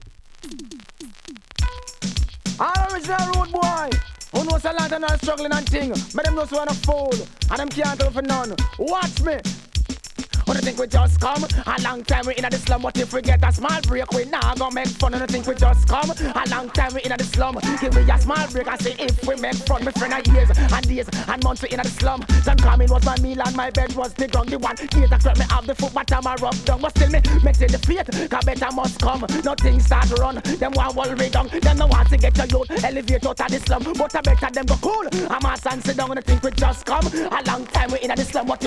REGGAE 90'S